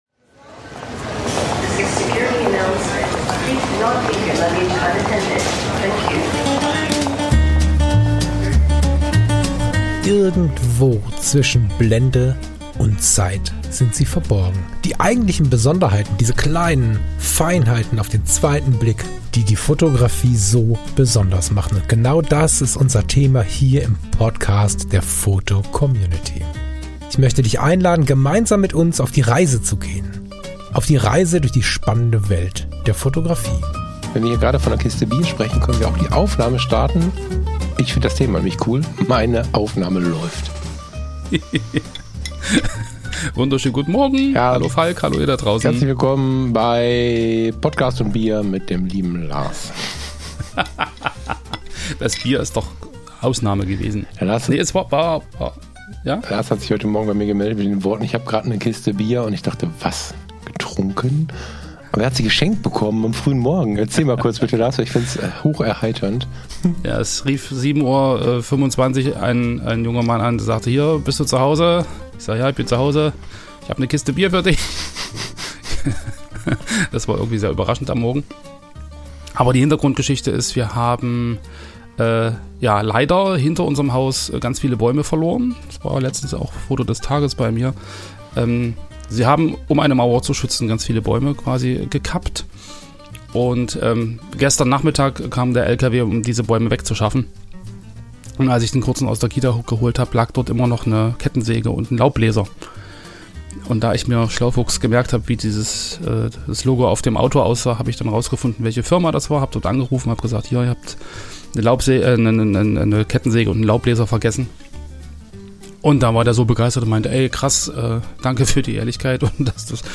456 - Manchmal trifft es den eigenen Standpunkt – Vom Hinterfragen der eigenen Bildhaltung ~ Zwischen Blende und Zeit - Der Fotografie-Talk der fotocommunity Podcast